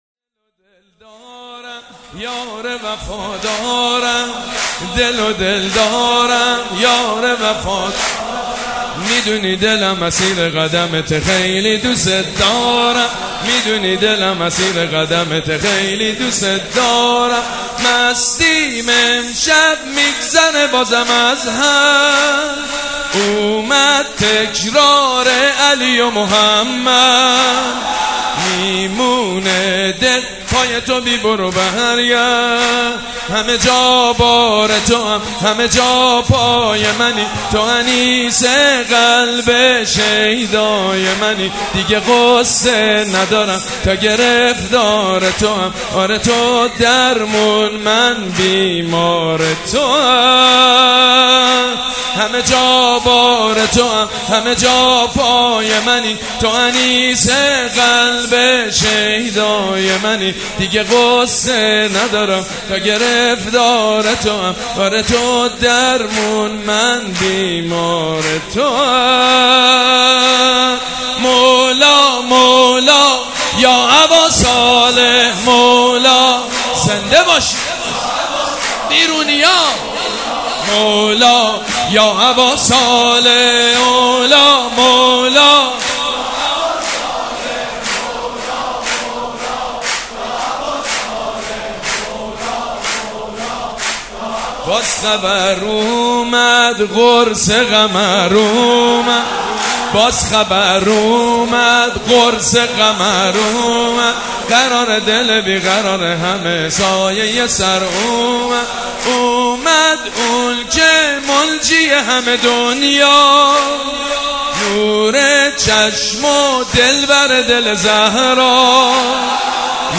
به مناسبت سالروز ولادت امام زمان(عج) سرودخوانی سیدمجید بنی‌فاطمه را می‌شنوید.
سرود